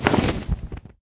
sounds / mob / enderdragon / wings5.ogg
wings5.ogg